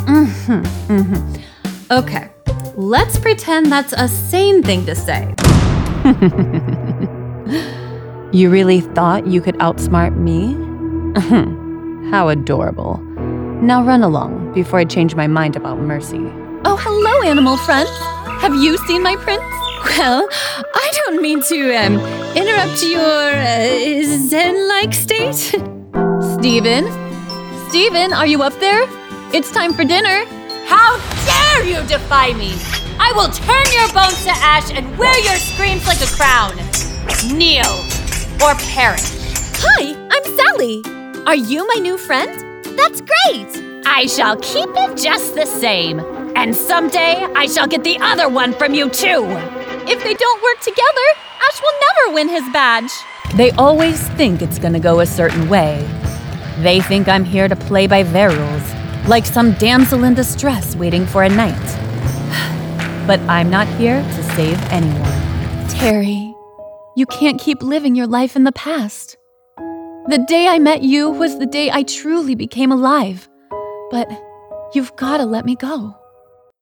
Yng Adult (18-29) | Adult (30-50)
Character, Animation, Cartoon Voices